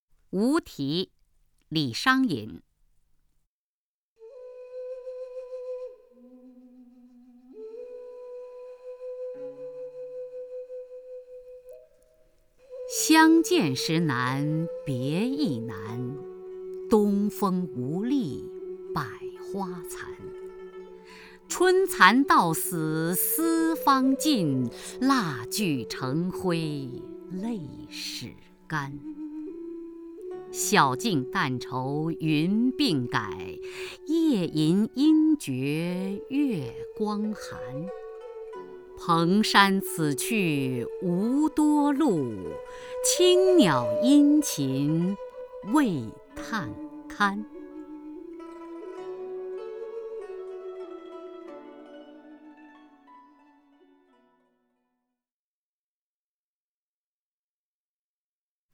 首页 视听 名家朗诵欣赏 雅坤
雅坤朗诵：《无题·相见时难别亦难》(（唐）李商隐)